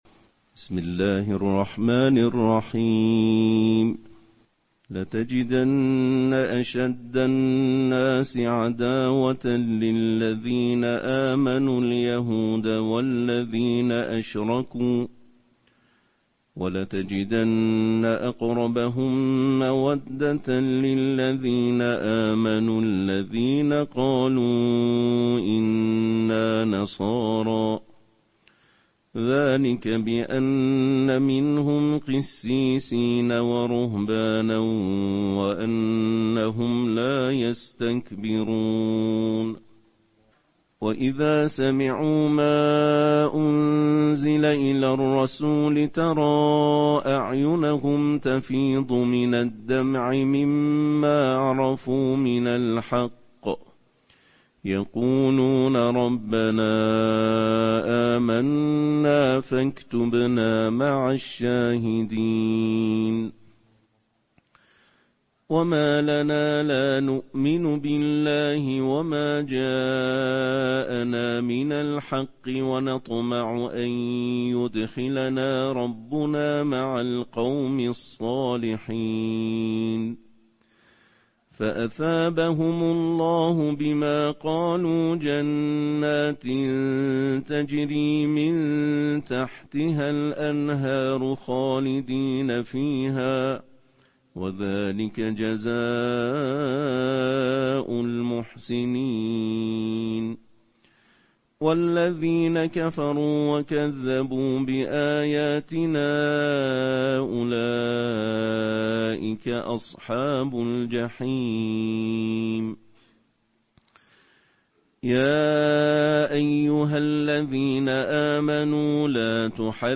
Pagbigkas ng Ikapitong Bahagi ng Quran na may Tinig ng Pandaigdigan na mga Mambabasa + Tunog
Araw ng Ramadan